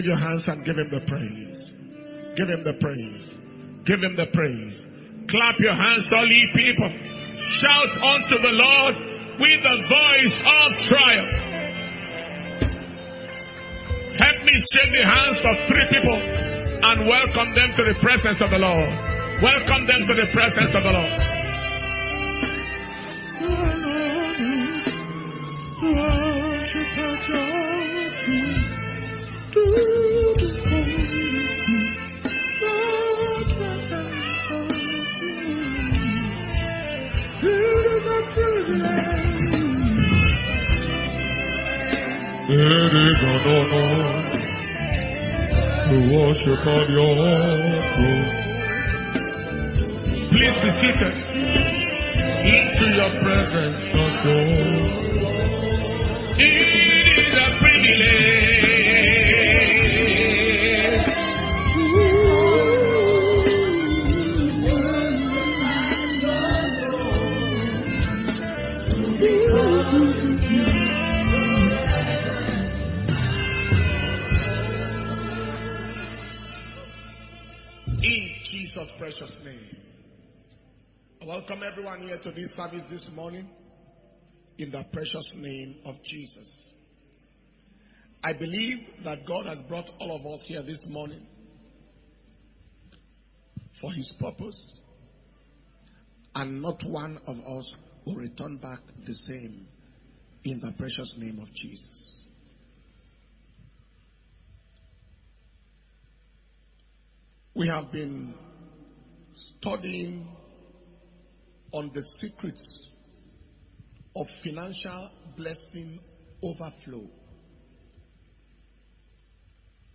Testimonies And Thanksgiving Service